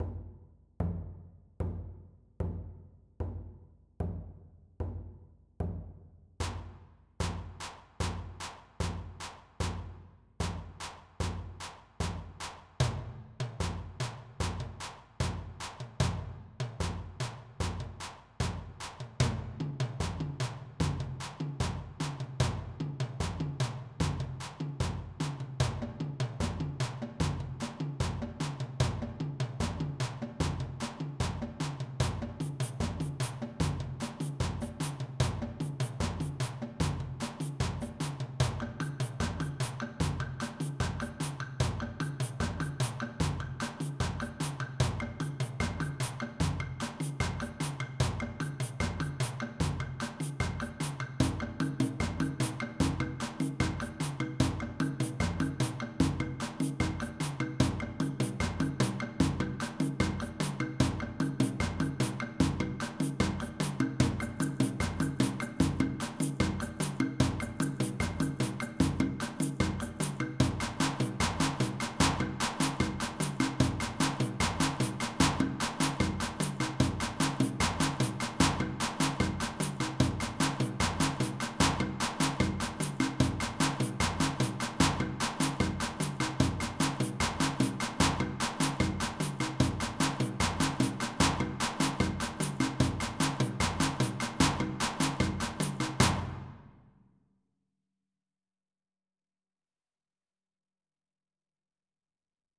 Balinese Kecak (Monkey Chant)
11 Part Polyrhythmic Kecak pdf
Kecak @ 150 ~~~